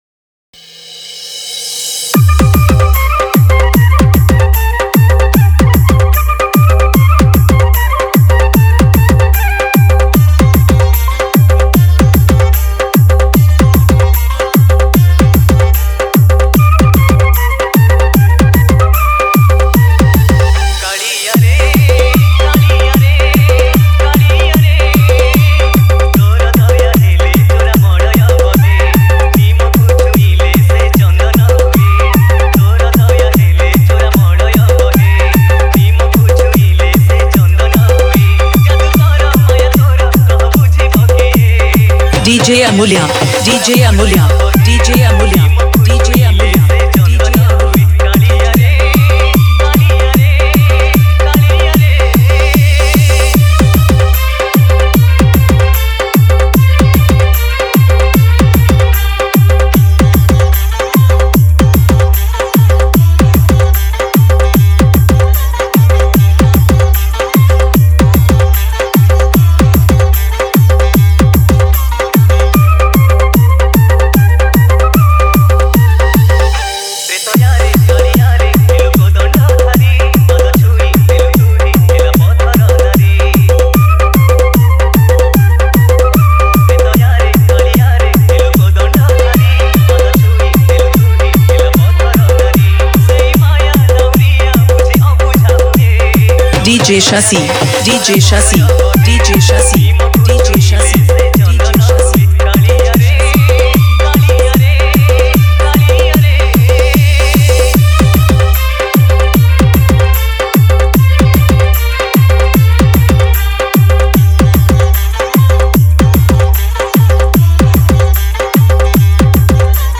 • Category:Odia New Dj Song 2018